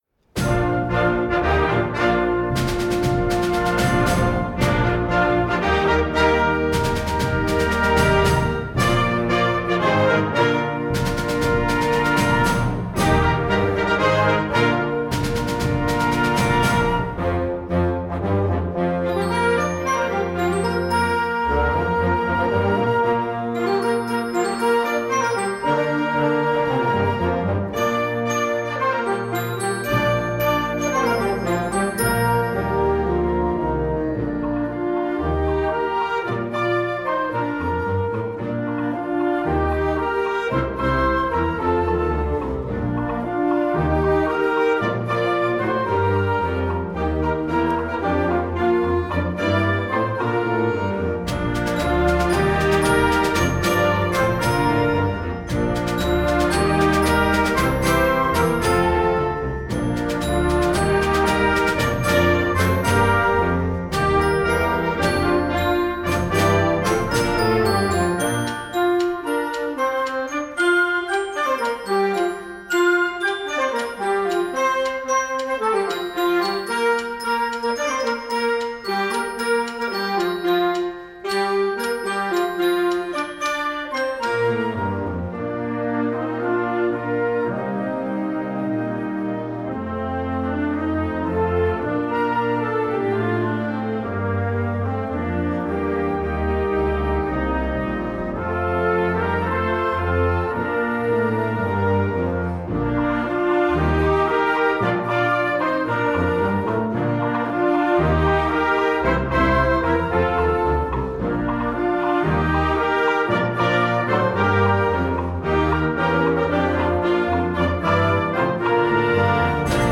Gattung: Konzertwerk für Jugendblasorchester
Besetzung: Blasorchester